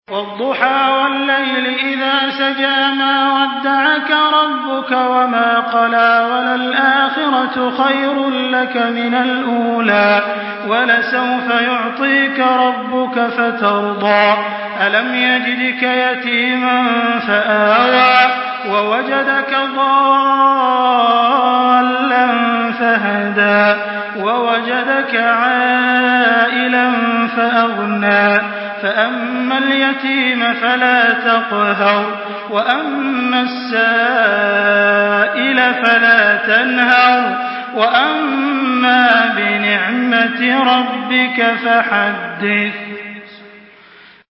Surah Ad-Duhaa MP3 by Makkah Taraweeh 1424 in Hafs An Asim narration.
Murattal Hafs An Asim